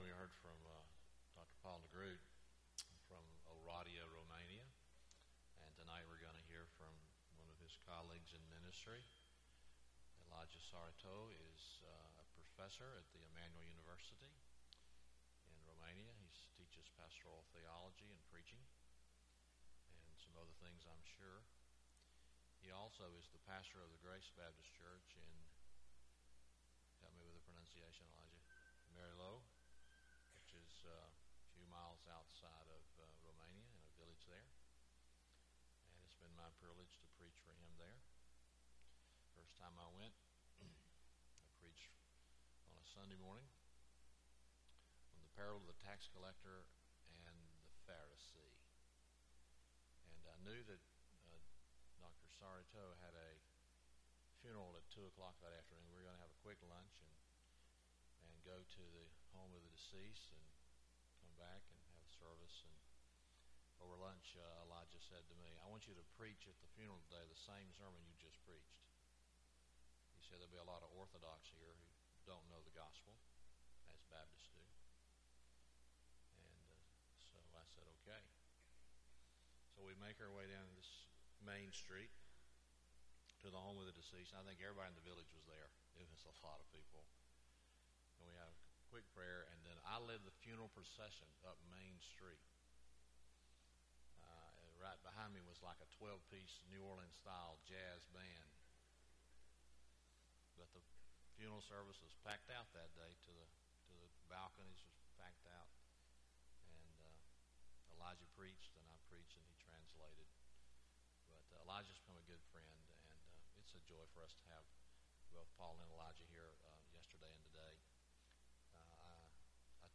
Guest Speaker
Sermon